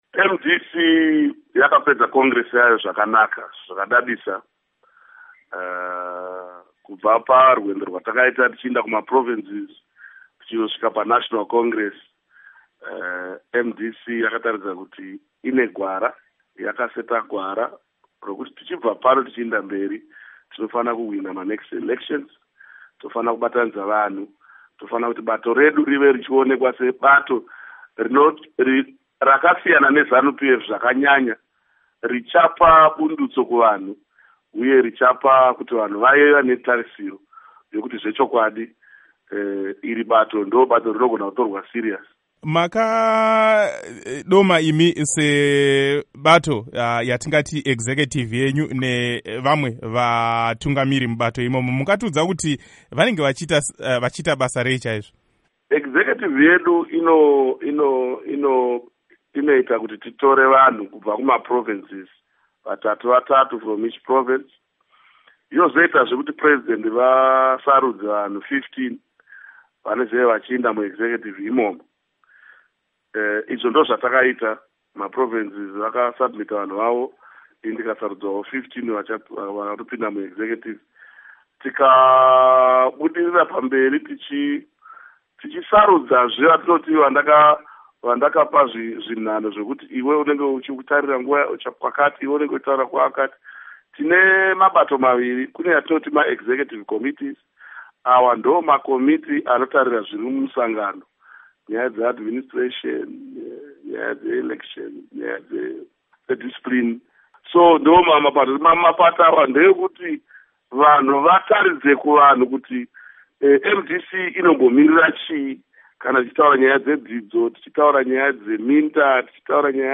Hurukuro naVaMorgan Tsvangiai